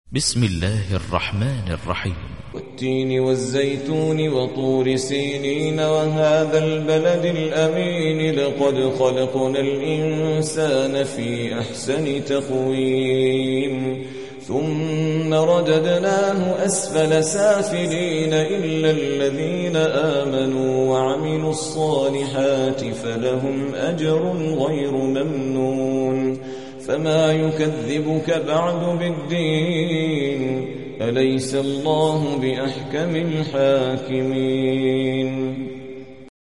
95. سورة التين / القارئ